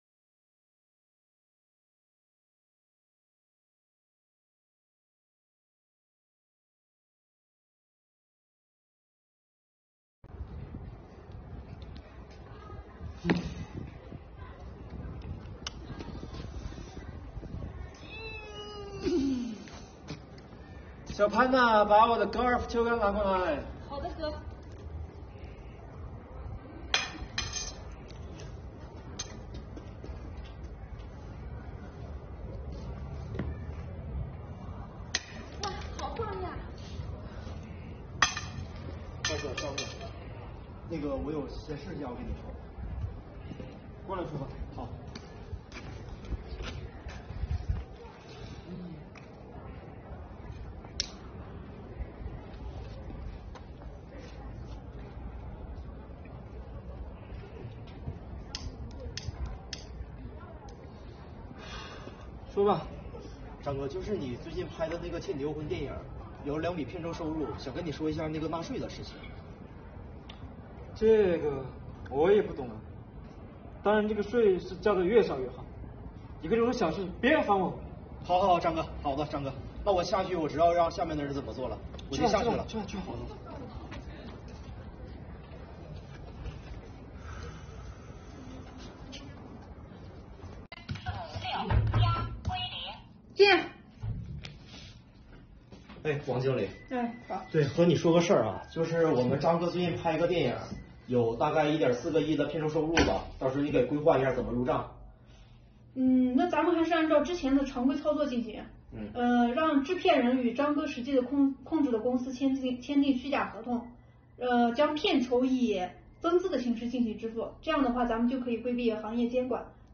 税收小剧场